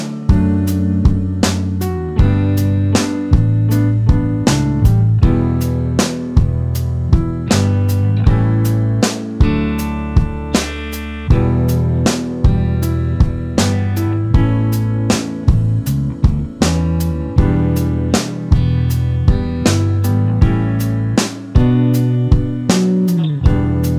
Minus Lead Guitars Pop